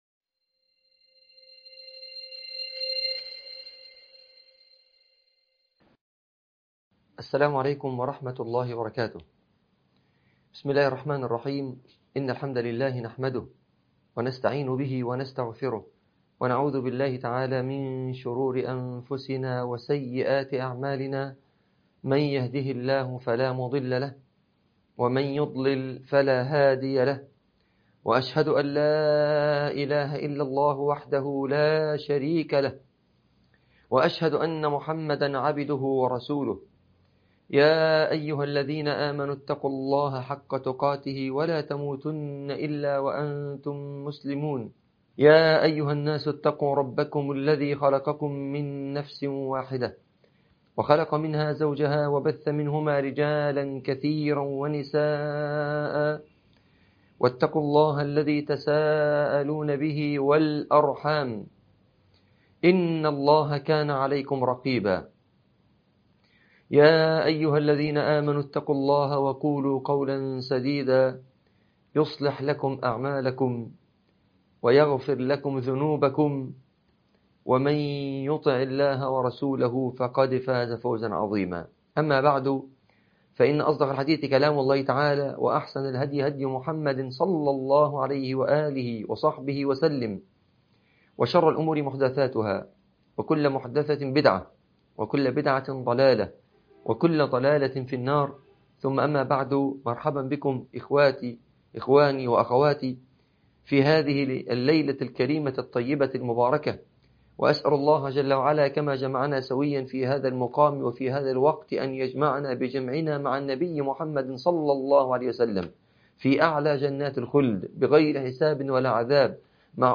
عنوان المادة السلف الصالح || محاضرة 3 || شرح كتاب حلية طالب العلم تاريخ التحميل الثلاثاء 23 يناير 2024 مـ حجم المادة 4.32 ميجا بايت عدد الزيارات 252 زيارة عدد مرات الحفظ 135 مرة إستماع المادة حفظ المادة اضف تعليقك أرسل لصديق